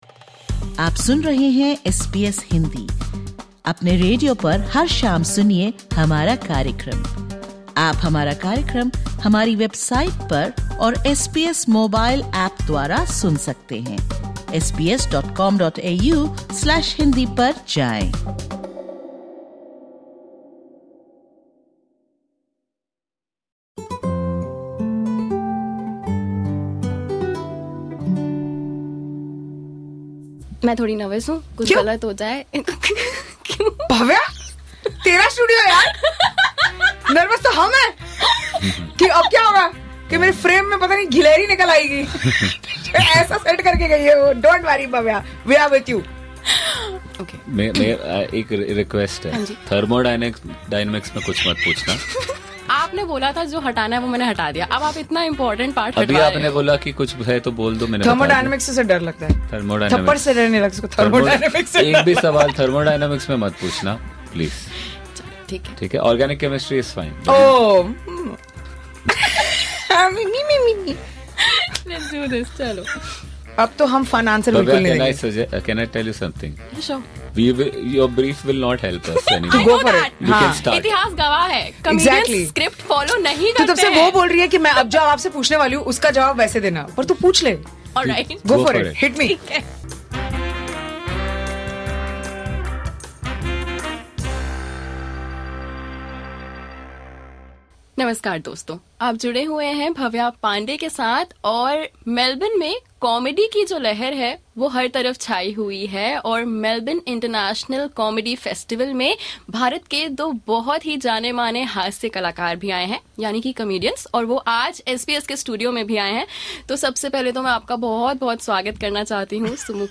The Melbourne International Comedy Festival has started, bringing together comedians from around the world. In this podcast, visiting Indian comedians Sumukhi Suresh and Rahul Subramanian share their experiences and reveal three things they want to take back to India.
Comedians Sumukhi Suresh and Rahul Subramanian visit the SBS Studio in Melbourne.